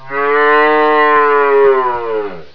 Cow
COW.WAV